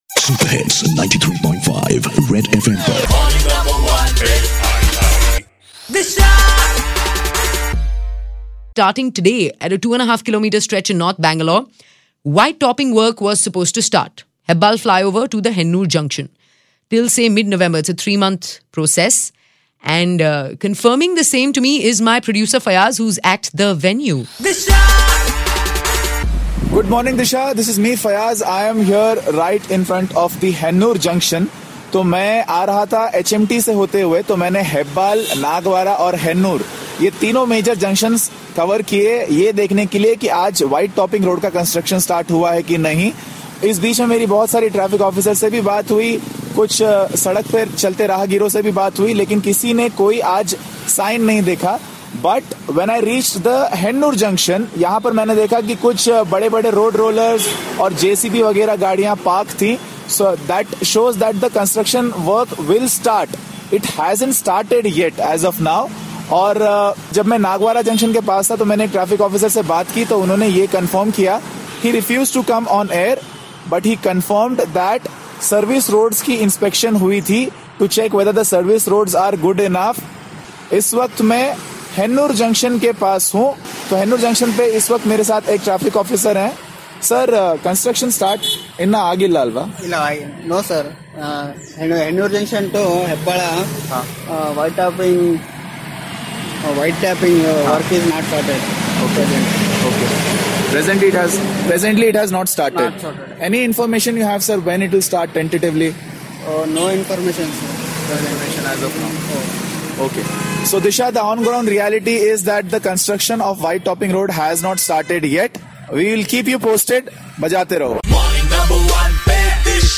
Live OB from Whitetopping Area